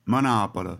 Monopoli (Italian: [moˈnɔːpoli]; Monopolitan: Menòpele [məˈnɔːpələ]